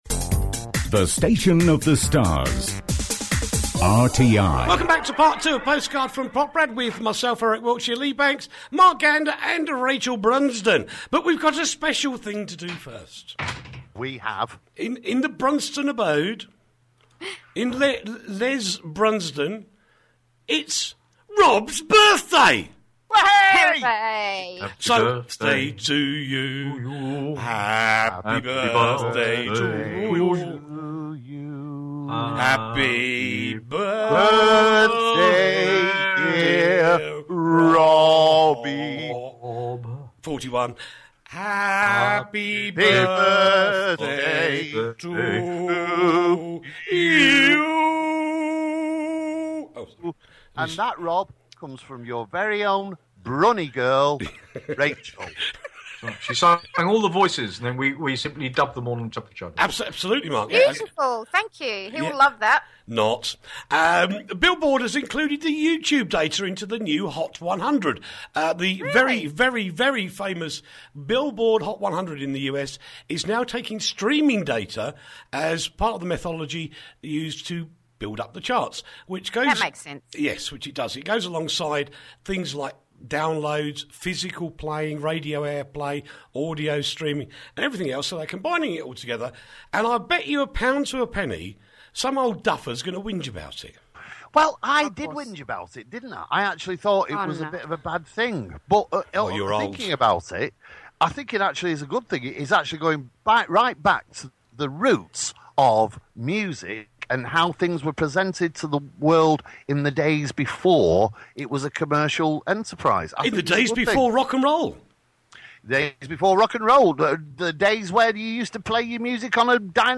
the alternative news show from Radio Tatras International